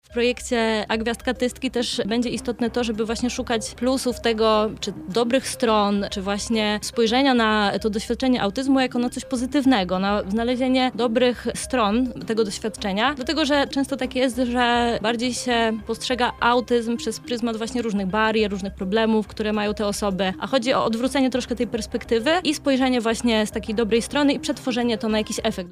Więcej na ten temat usłyszeliśmy podczas Porannej Rozmowy Radia Centrum.